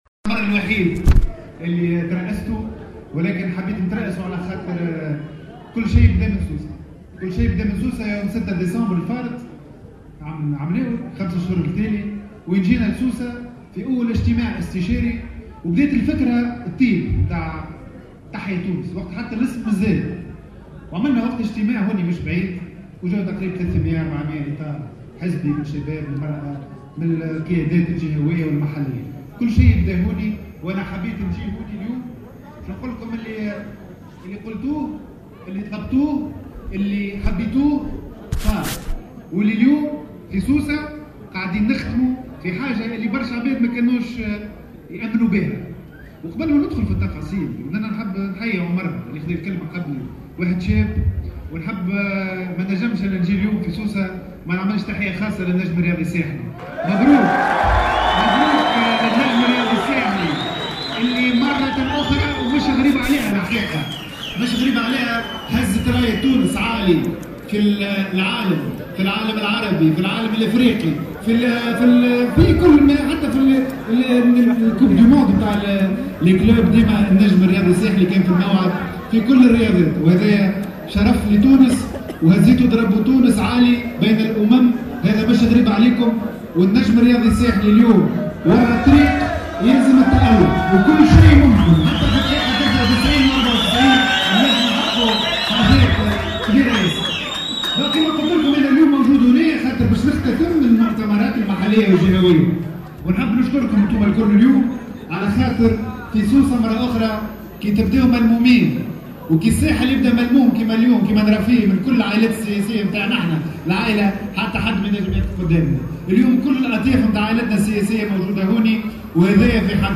M. Azzabi a également indiqué, dans une déclaration à Mosaïque FM, hier soir, mercredi 24 avril, qu’en l’espace de 5 mois, Tahya Tounes est devenu l’une des forces politiques les plus importantes dans le pays.